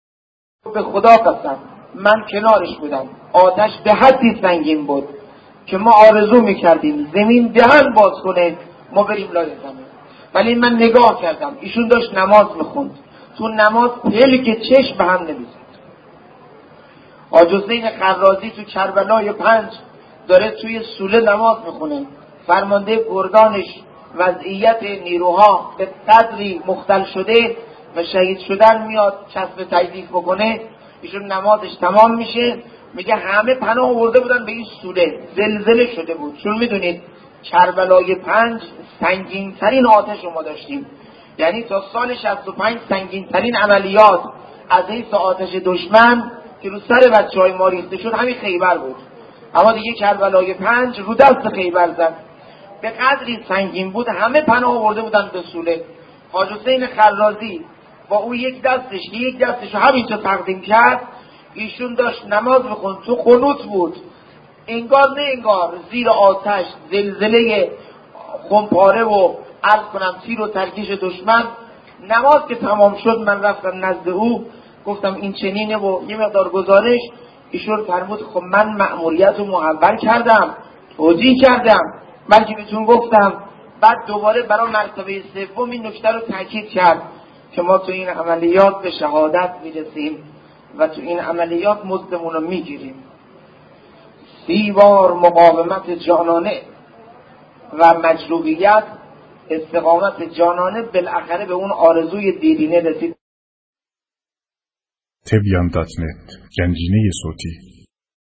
صوت روایتگری
ravayatgari60.mp3